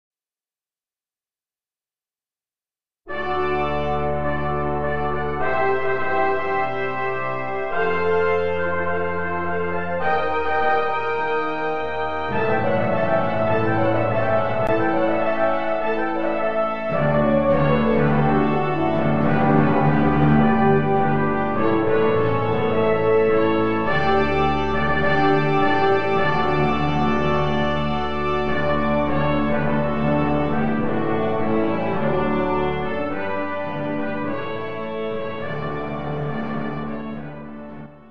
Brass Quintet and Timpani Arrangement
Brass Quintet with Fanfare
and Trumpet Descant